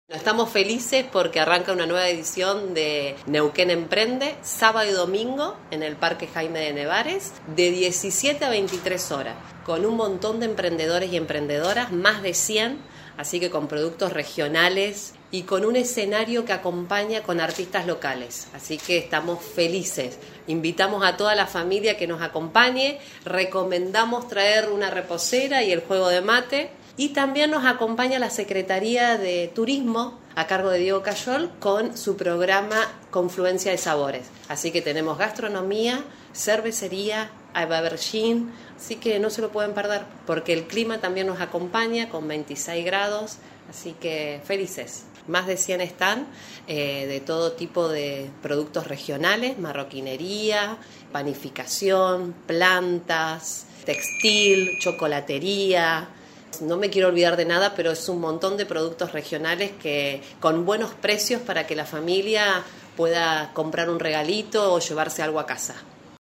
Morena Traversi, subsecretaria de Coordinación y Enlace.